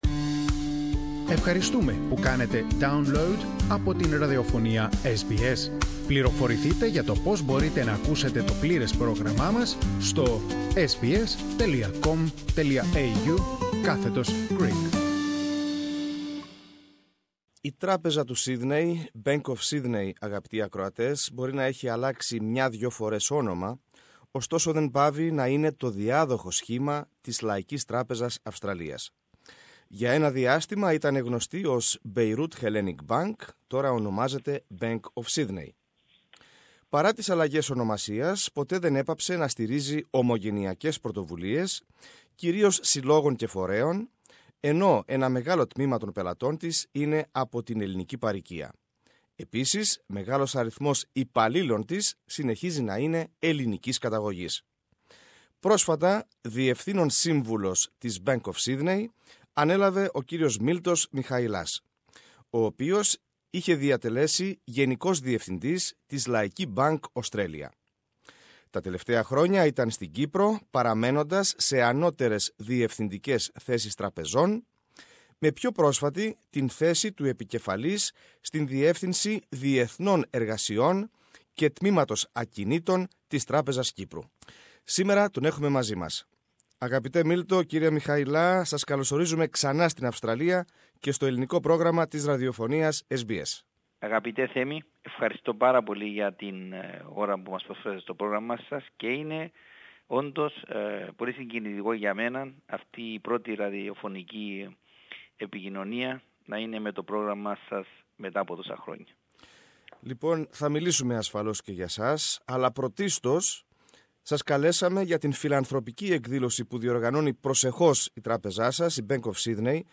Περισσότερα ακούμε στην συνέντευξη